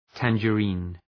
{,tændʒə’ri:n}
tangerine.mp3